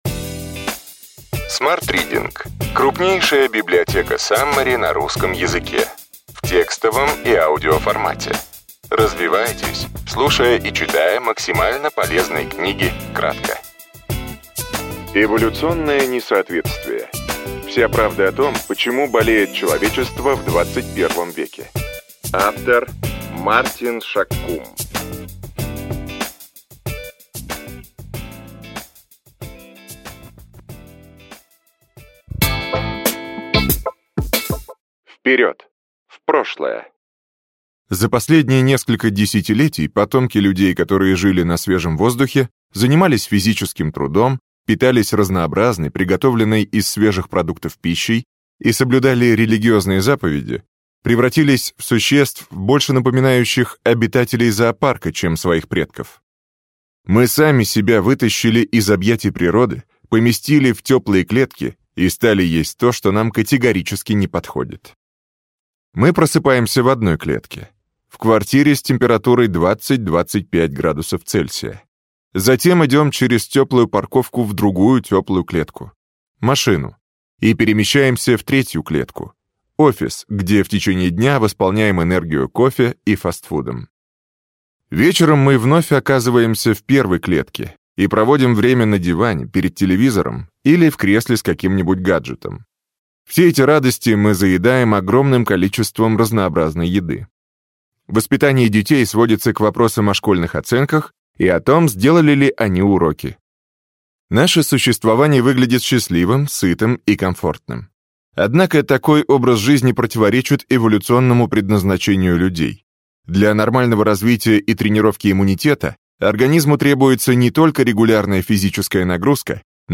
Аудиокнига Эволюционное несоответствие. Вся правда о том, почему болеет человечество в XXI веке.